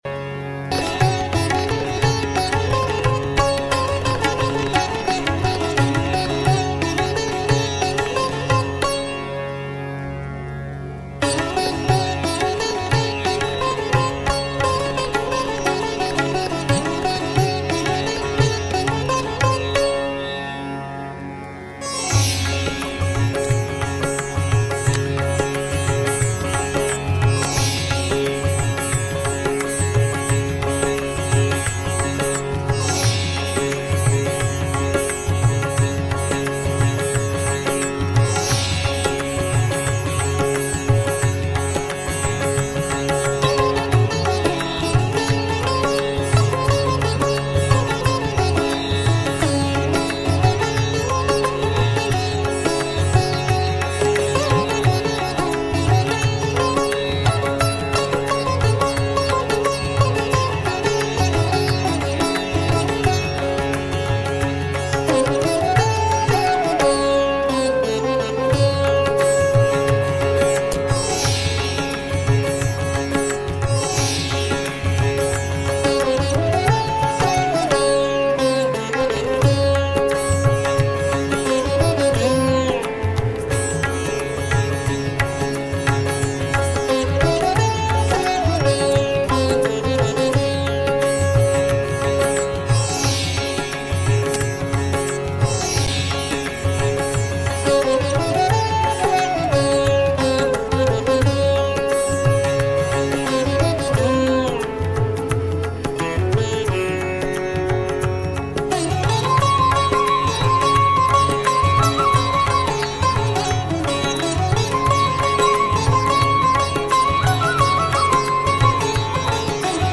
• Для учителя - изображение разных бабочек, карточки с изображением разных предметов (для работы над симметрией), аудиозапись "Этническая музыка мира - "Полет бабочки" - Индия, презентация "Бабочки", кисти, акварель.